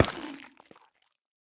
Minecraft Version Minecraft Version snapshot Latest Release | Latest Snapshot snapshot / assets / minecraft / sounds / block / sculk_catalyst / step6.ogg Compare With Compare With Latest Release | Latest Snapshot
step6.ogg